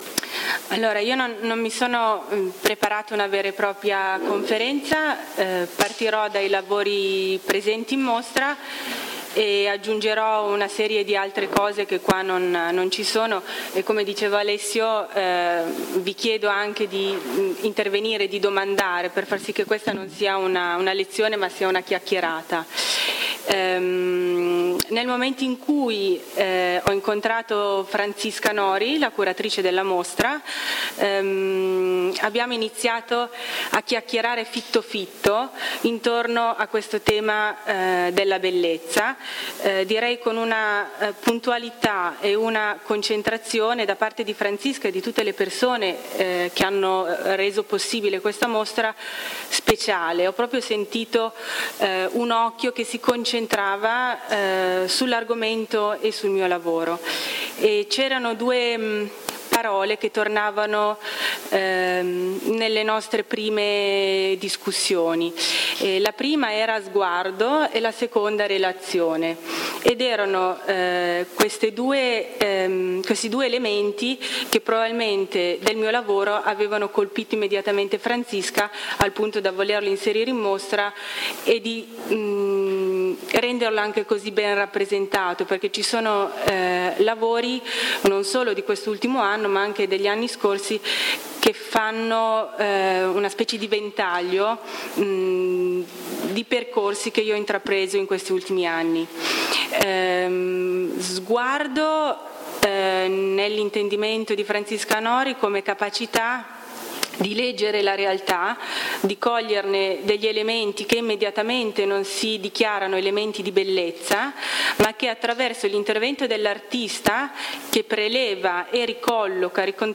ARTIST TALK